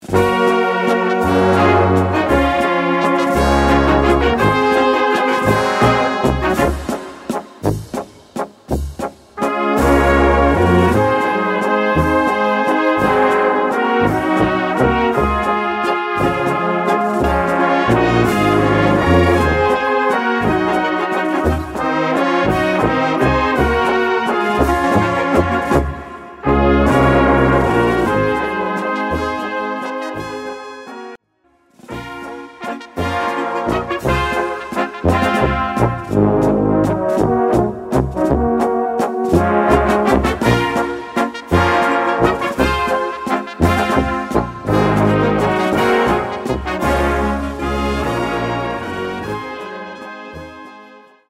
Gattung: Walzer für Blasorchester
Besetzung: Blasorchester